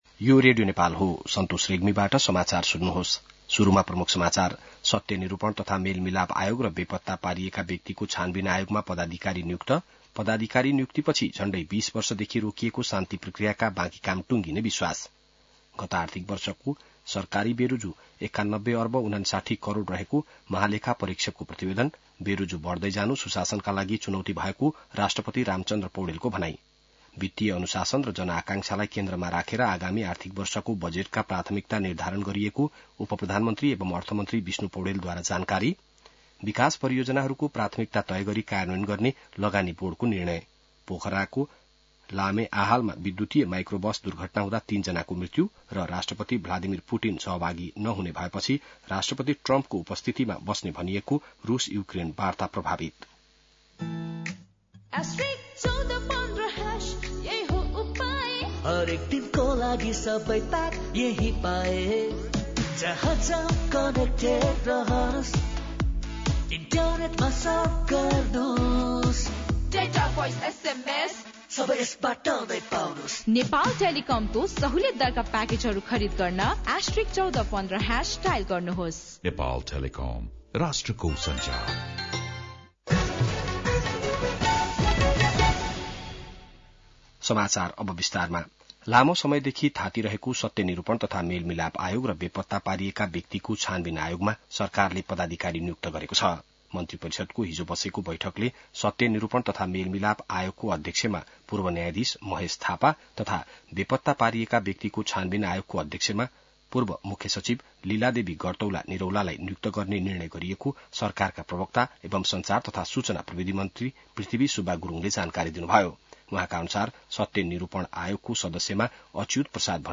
बिहान ७ बजेको नेपाली समाचार : १ जेठ , २०८२